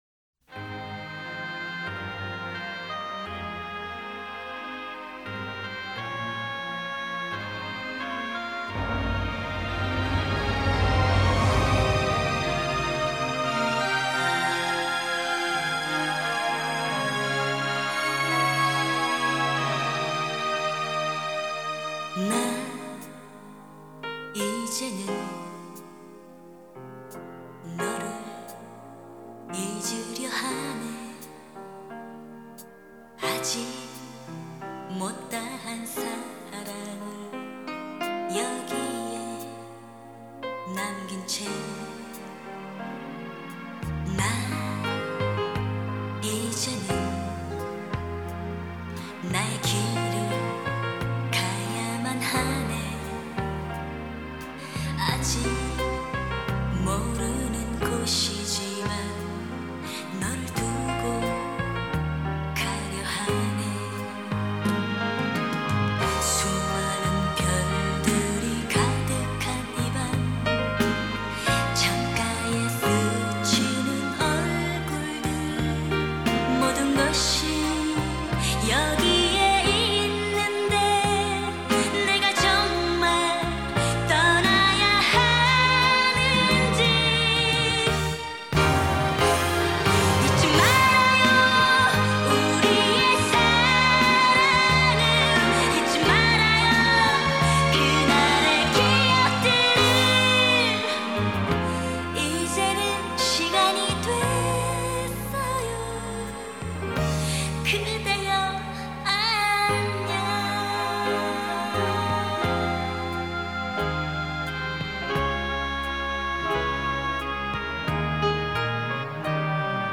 대한민국의 여성 싱어송라이터, 작사가, 작곡가, 음반 프로듀서, 배우이다.